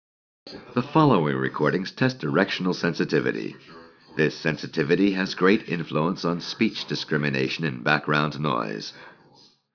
Aquí escucharemos a un hombre hablando en un ambiente con conversaciones de fondo, grabado mediante diferentes configuraciones de micrófonos.
La segunda corresponde a un sistema GSC y la última, la mejor, corresponde a un sistema AEC-GSC.
AEC-GSC output.wav